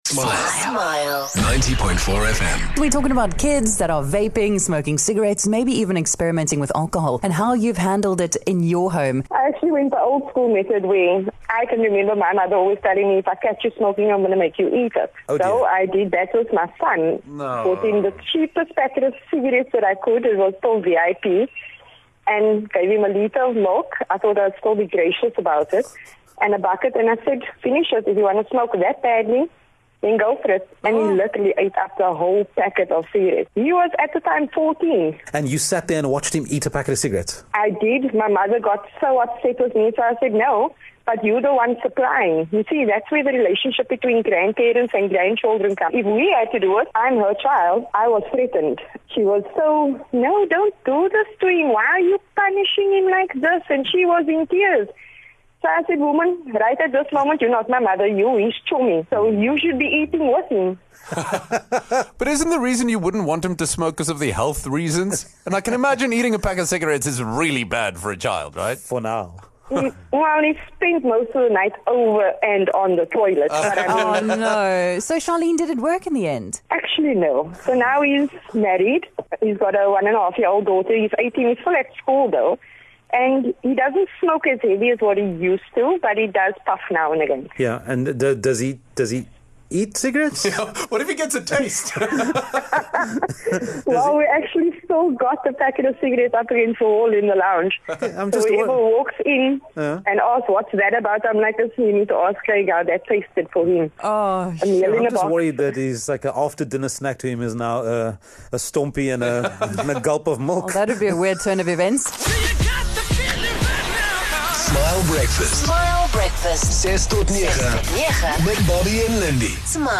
Listeners called in and shared their thoughts on how to approach the topic with their kids, but a particular call caught us off-guard.